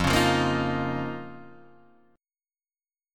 F7sus4#5 chord